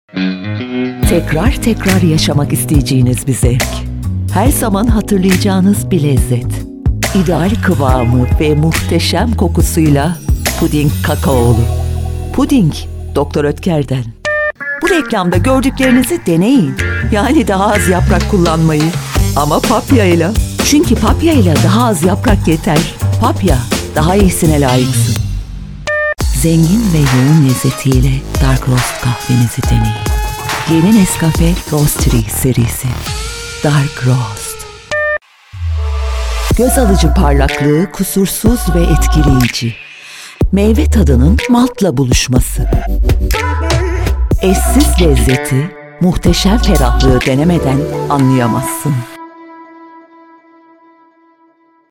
Listen to Dubbing Cast Microphone Players!